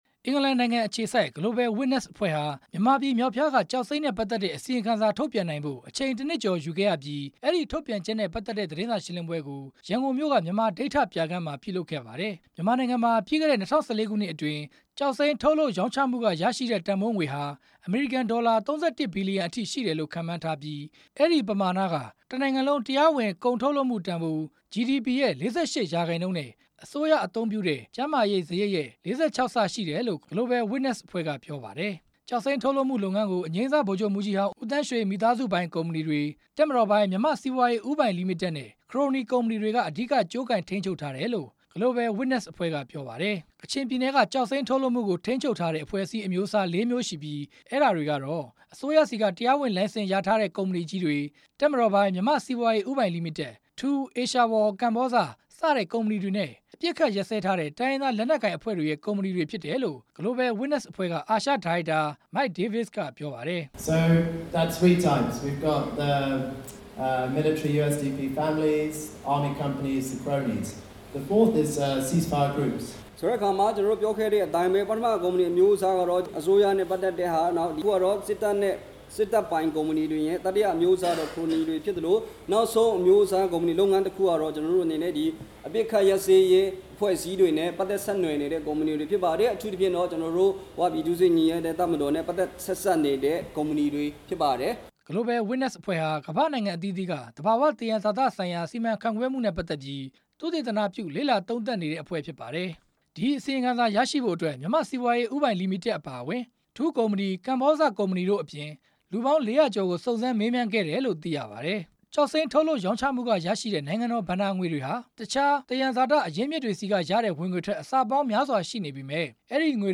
ကောက်နှုတ်တင်ပြချက်